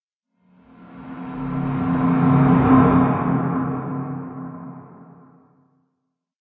cave6.ogg